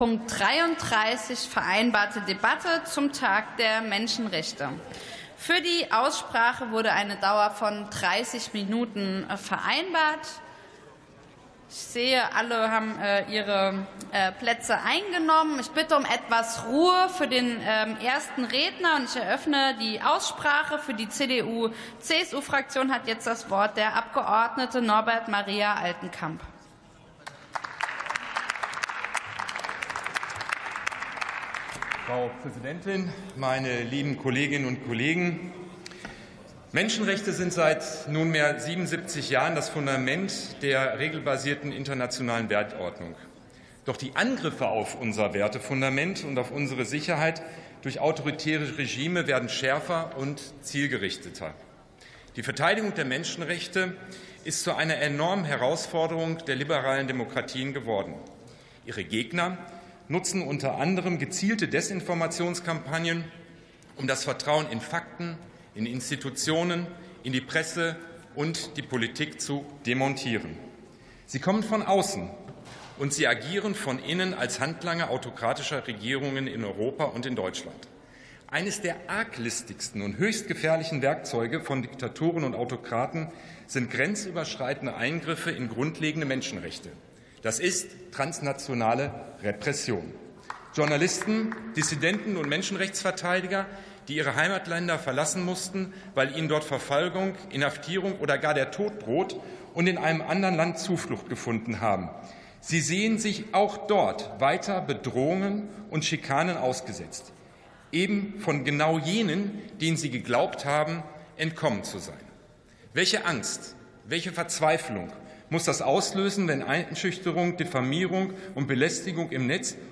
47. Sitzung vom 04.12.2025. TOP 33: Menschenrechte ~ Plenarsitzungen - Audio Podcasts Podcast